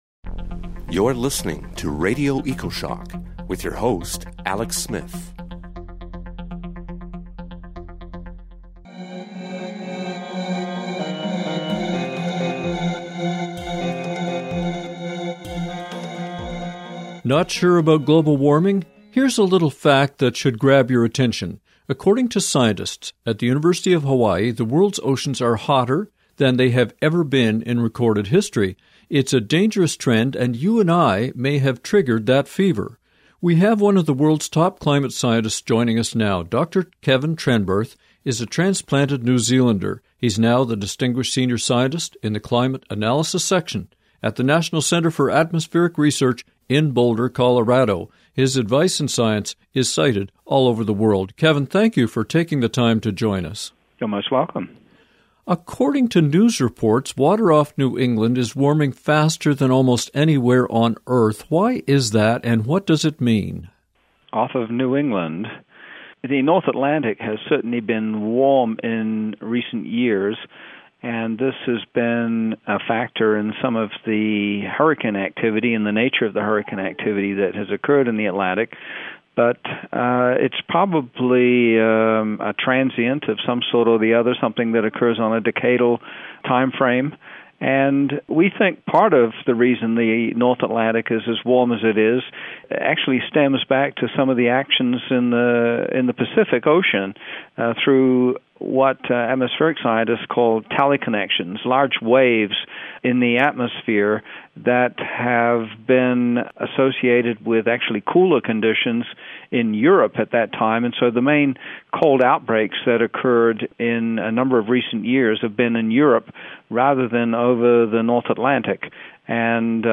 We’ll talk with Dr. Kevin Trenberth, one of the world’s top climate scientists.